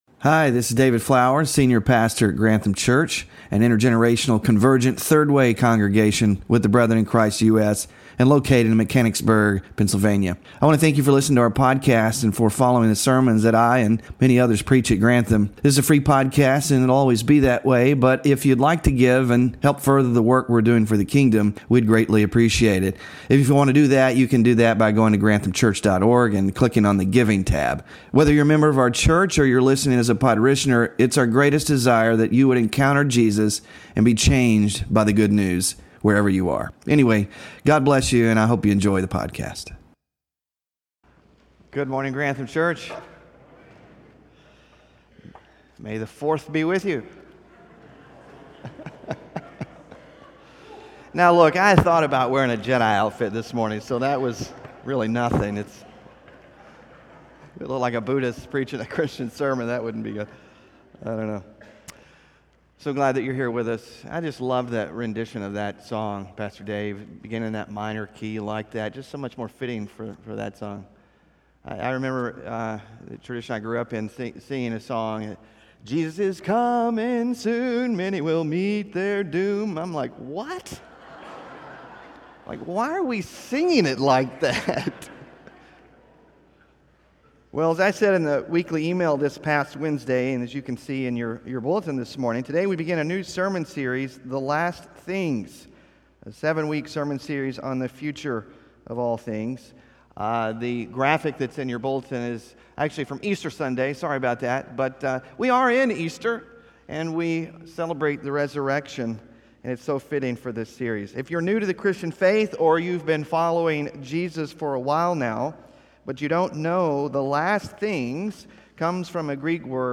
WORSHIP RESOURCES EXISTENTIAL THREATS, ETERNITY & THE ESCHATON - SERMON SLIDES 1 of 7 (5-4-25) SMALL GROUP QUESTIONS (5-4-25) BULLETIN (5-4-25) TIM MACKIE ON MATTHEW 24